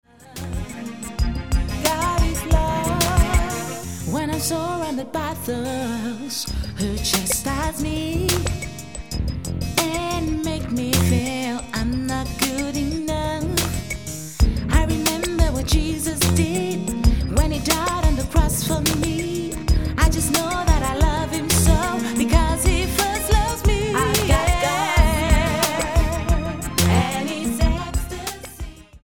London-based gospel singer
Style: Gospel